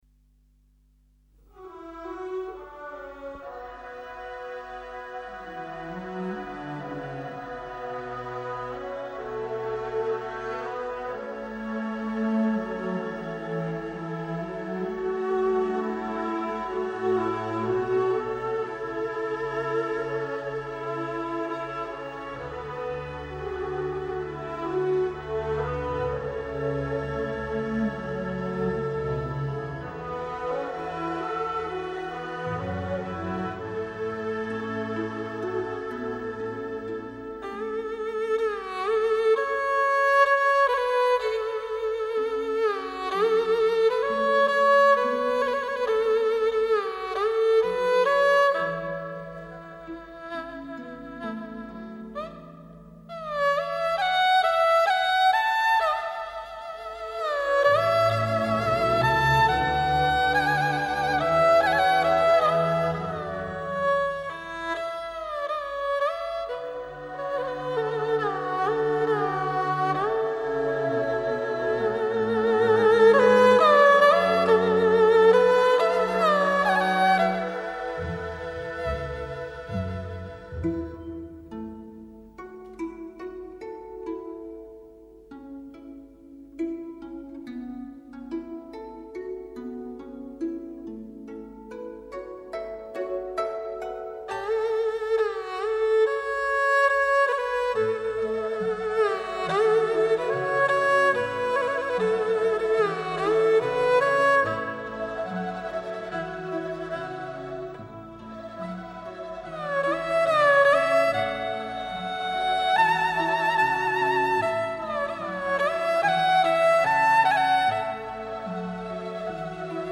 就是她－－著名二胡演奏家姜建华，当年(1978年)为小泽征尔演奏了这首二胡曲子。
《二泉映月》， 舒缓而缠绵的旋律在优柔的琴弦里轻述， 月光无言地听着泉水的述说， 水边的阿炳往如隔世的幽灵坐在泉边......